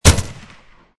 SoldierSoundsPack
fire.mp3